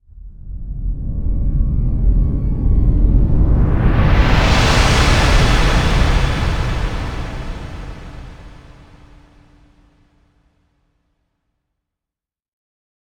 debrishum.ogg